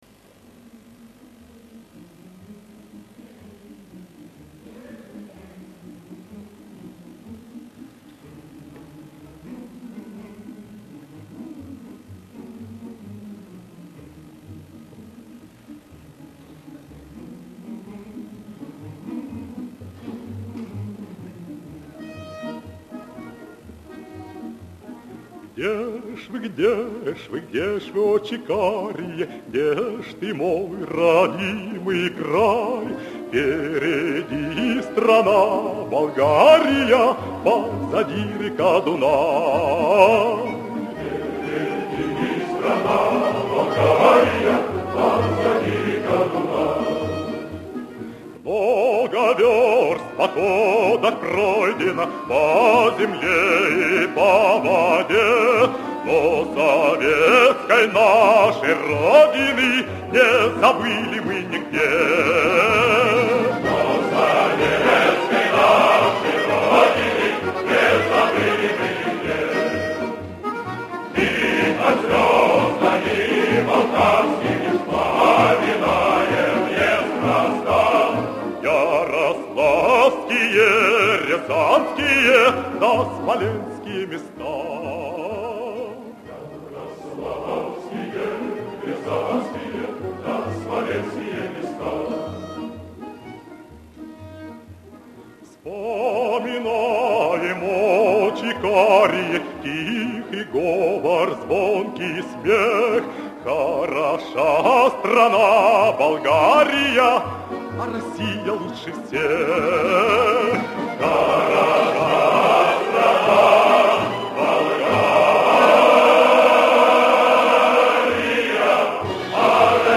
Запись хорошая, хотя немного старые, в общественных местах.